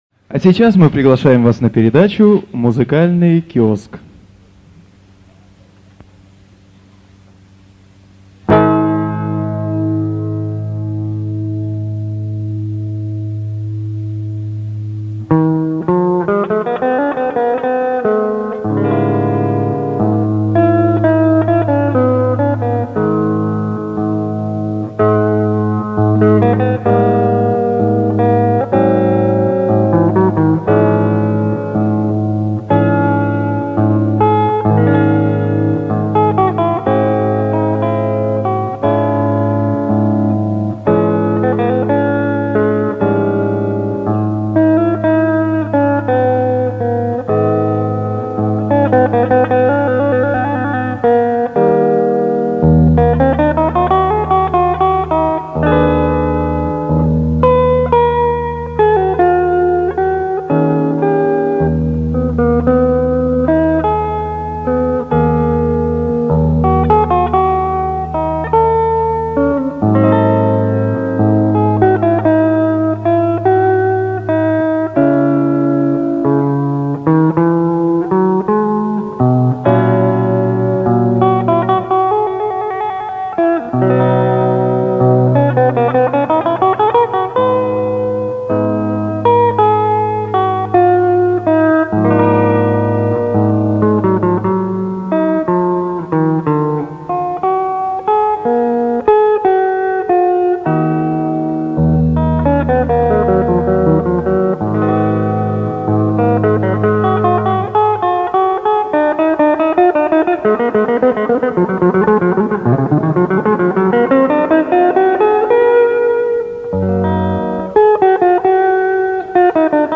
инструментальная композиция на музыку Баха.